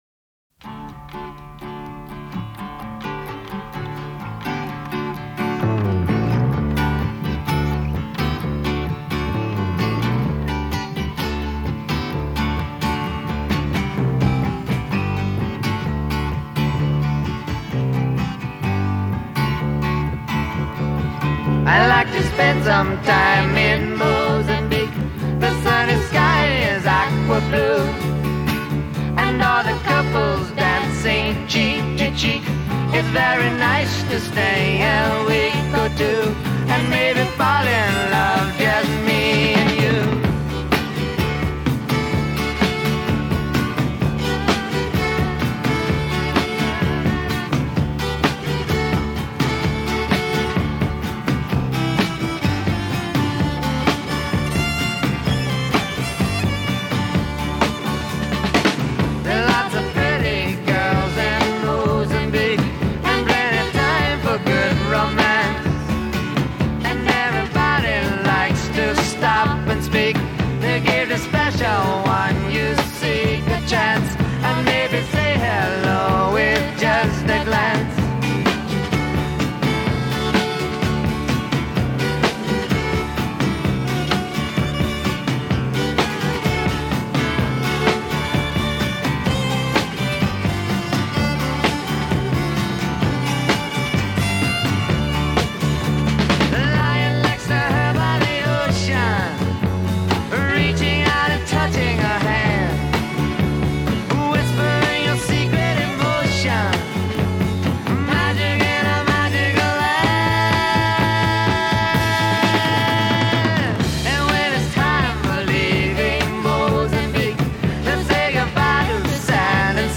… lite islands sound… though the irony is thick.